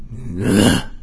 zo_attack1.wav